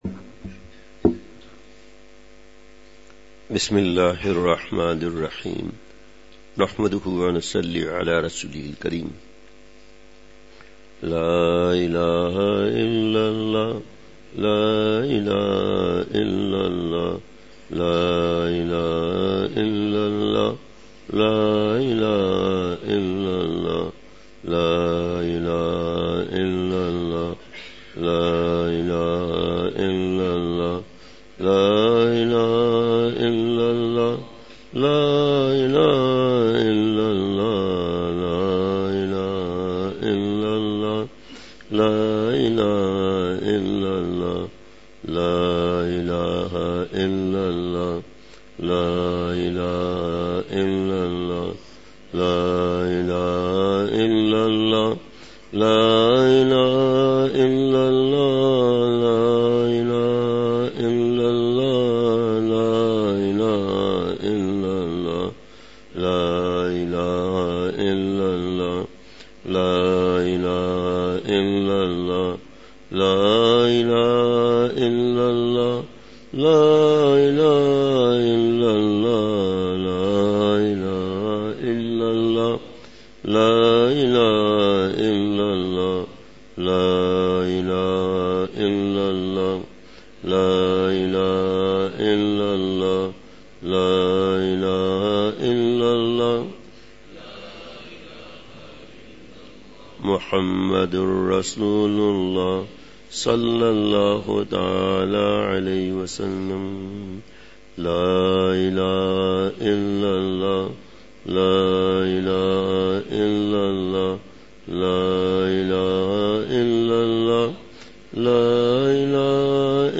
فجر مجلس۱۴ دسمبر ۲۵ء:حسن فانی کی عارضی چمک دمک بہت بڑا دھوکہ ہے !
مقام:مسجد اختر نزد سندھ بلوچ سوسائٹی گلستانِ جوہر کراچی